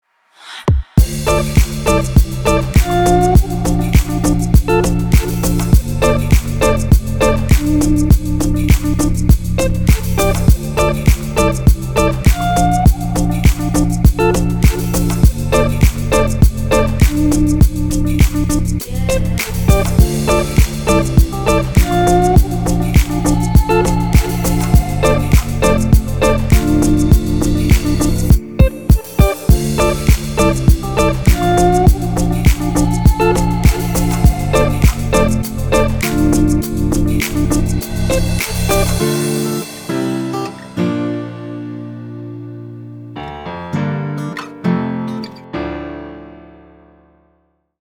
• Качество: 320, Stereo
гитара
deep house
приятные
медленные
летние
чилловые